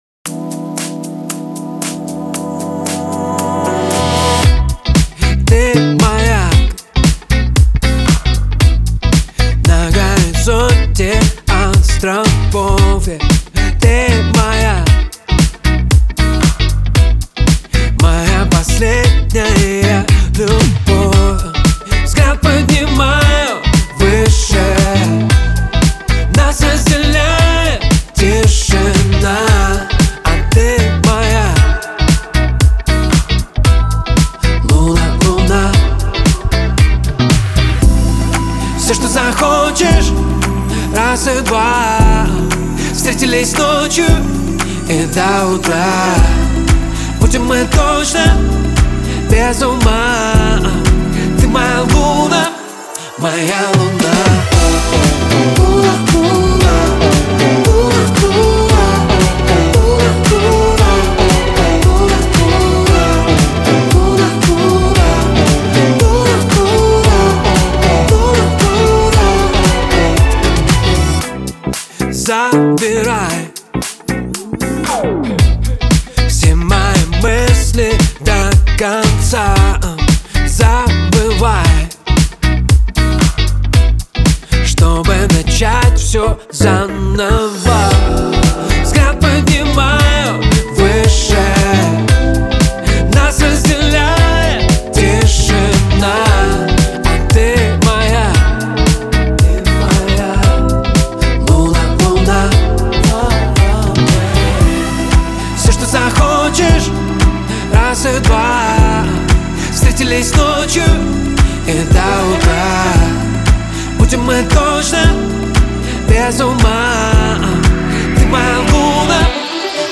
Шансон , Лирика